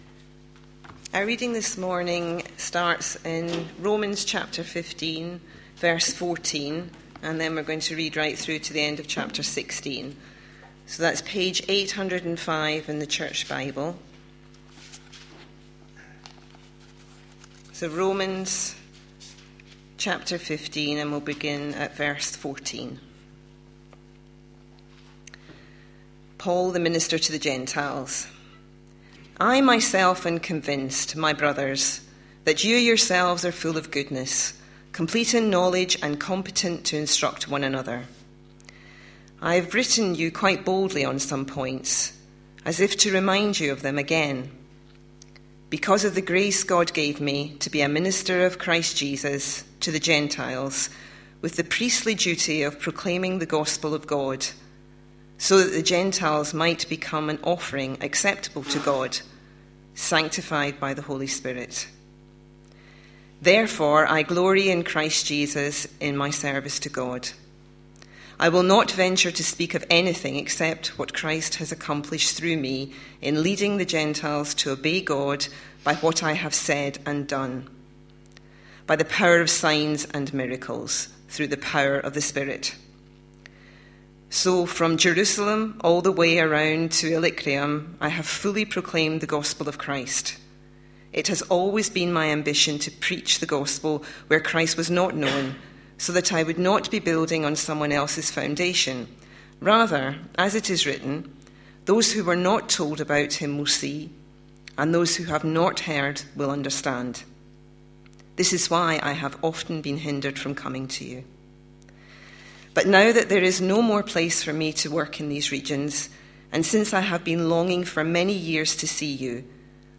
A sermon preached on 24th March, 2013, as part of our Romans series.